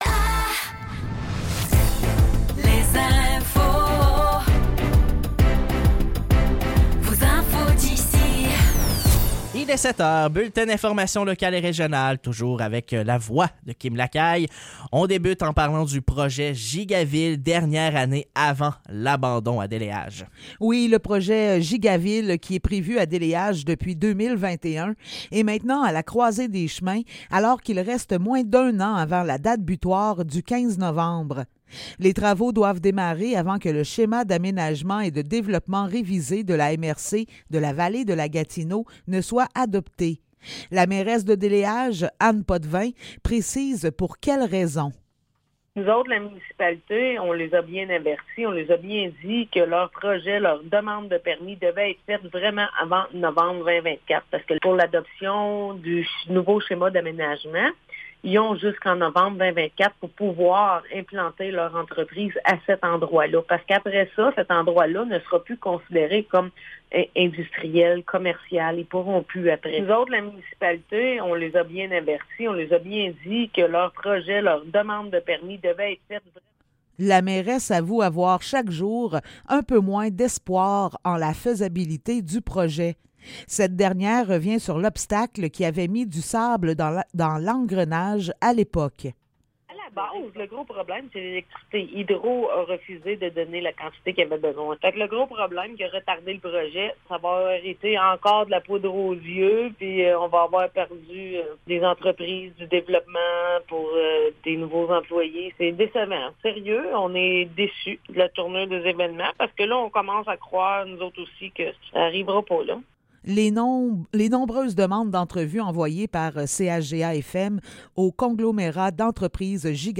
Nouvelles locales - 14 décembre 2023 - 7 h